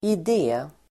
Uttal: [id'e:]